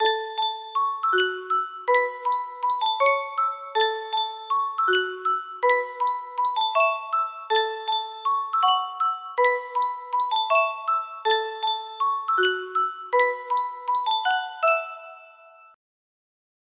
Yunsheng Music Box - Unknown Tune 1118 music box melody
Full range 60